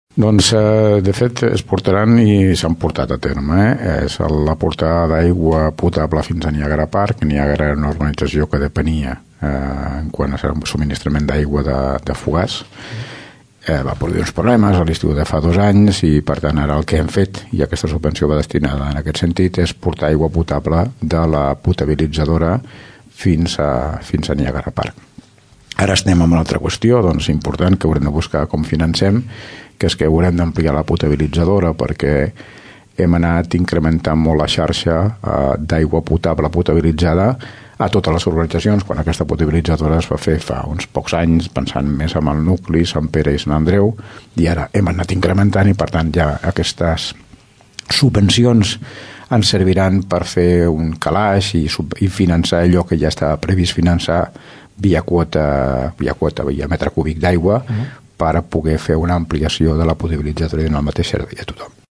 Aquests diners serviran per portar aigua potable fins a la urbanització Niàgara Park i també per ampliar la potabilitzadora del municipi. Ho explica l’Alcalde de Tordera, Joan Carles Garcia.